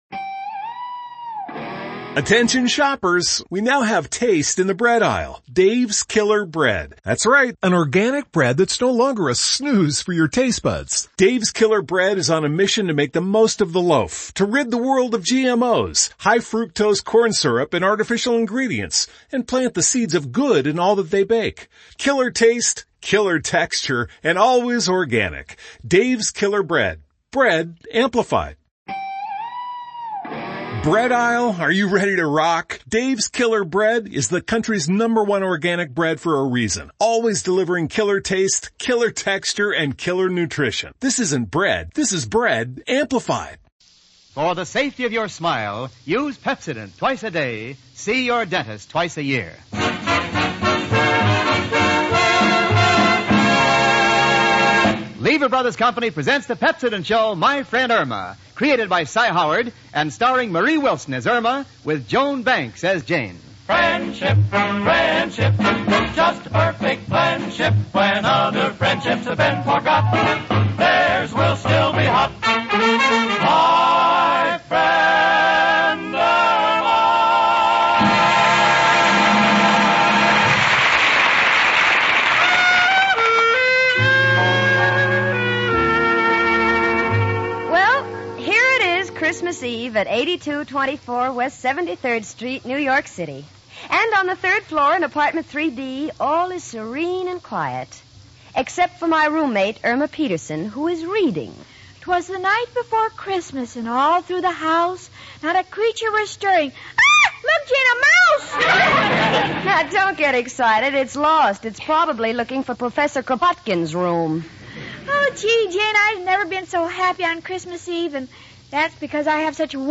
"My Friend Irma," the classic radio sitcom that had audiences cackling from 1946 to 1952! It was a delightful gem of a show, chronicling the misadventures of Irma Peterson, a ditzy yet endearing blonde, and her level-headed roommate Jane Stacy. Irma, played to perfection by the inimitable Marie Wilson, was the quintessential "dumb blonde."